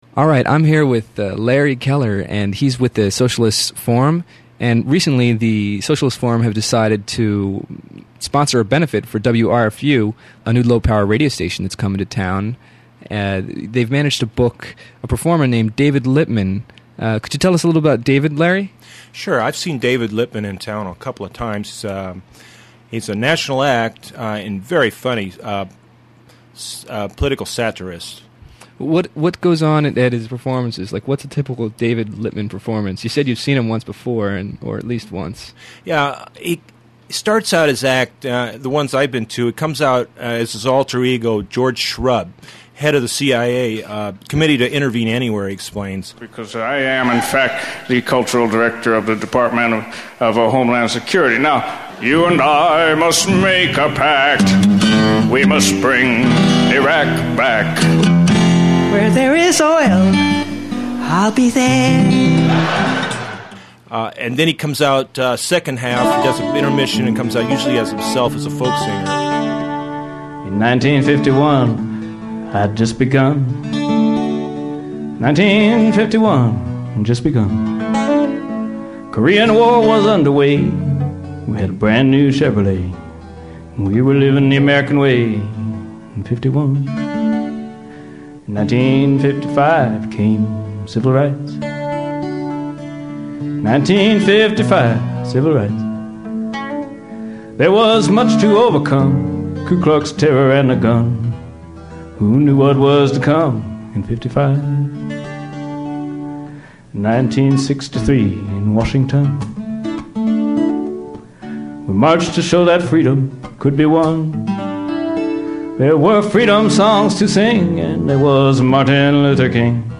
Political satirist/songwriter